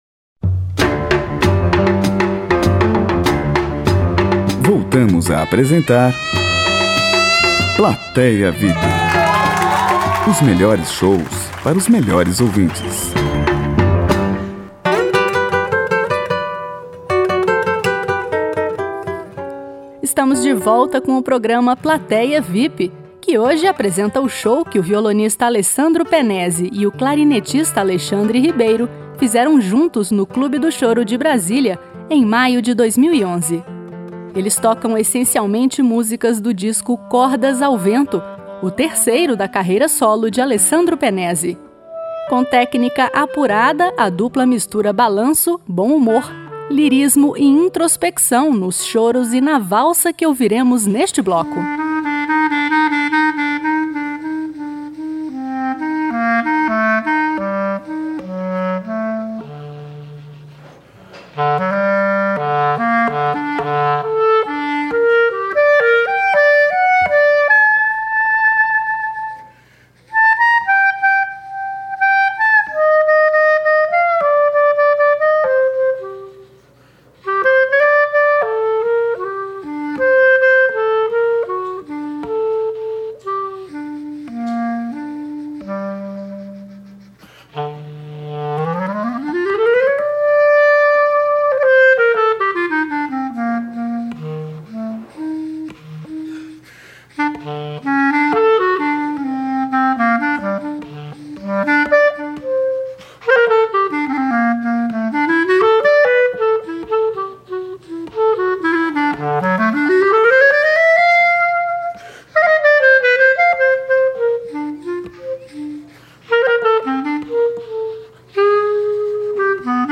Música Brasileira